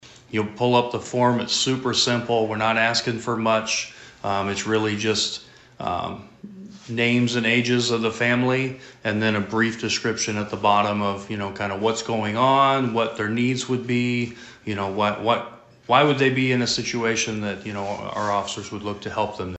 Atlantic Police Chief Devin Hogue says applications can be picked up at the Police Station and returned.